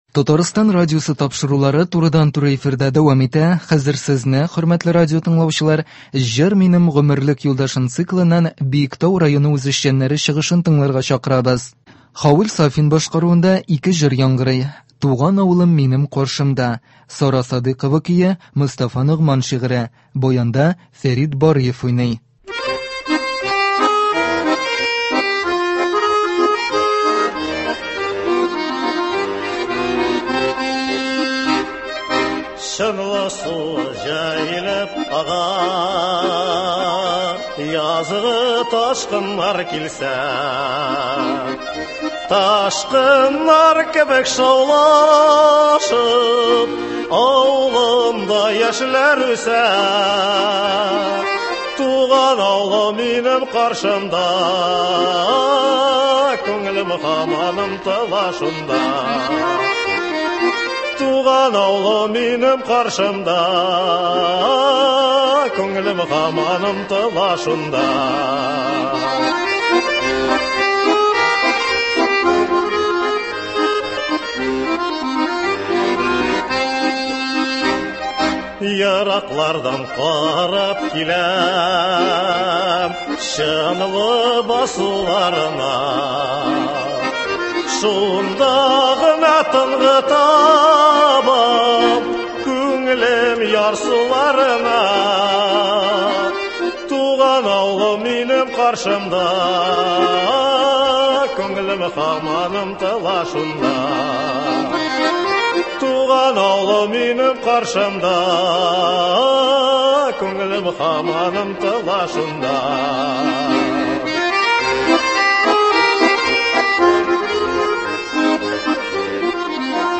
Концерт (28.03.22)